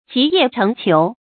集腋成裘 注音： ㄐㄧˊ ㄧㄜˋ ㄔㄥˊ ㄑㄧㄡˊ 讀音讀法： 意思解釋： 集：聚集；腋：狐腋下的皮毛。